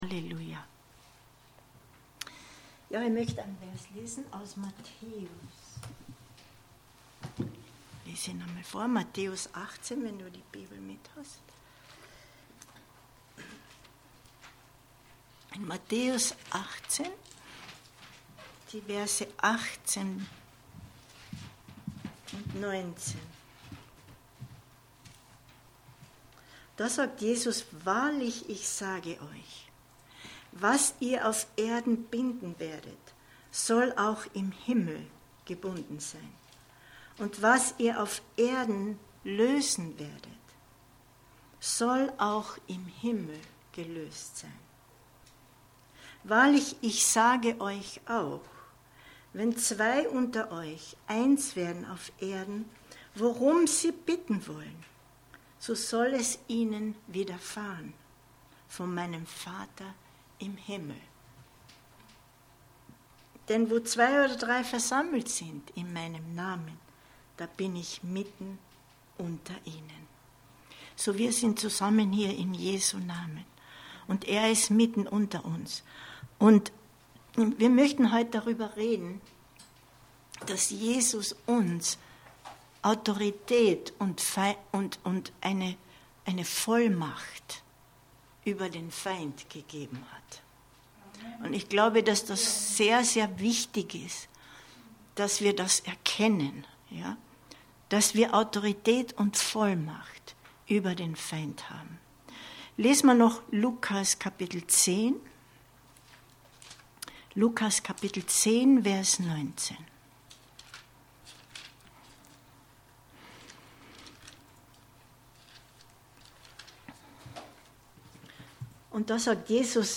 Aufnahme des Bibelabends vom Mittwoch, 05.10.2022